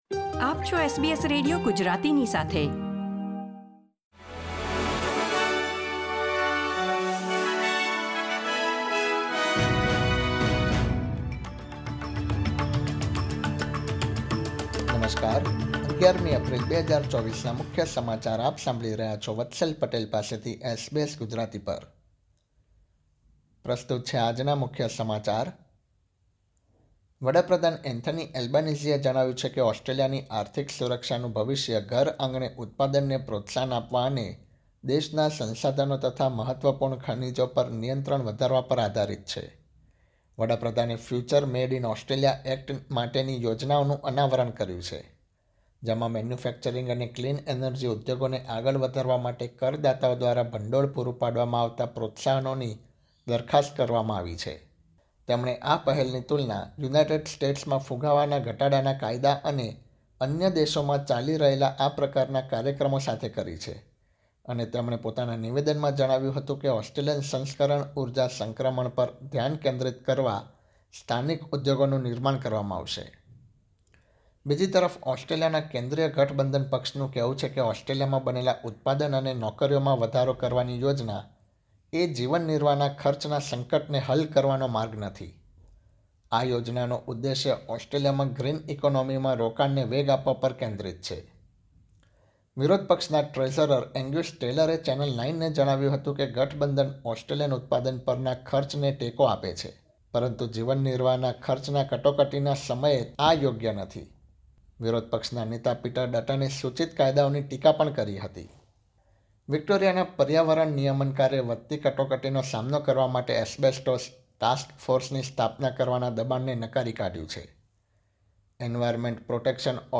SBS Gujarati News Bulletin 11 April 2024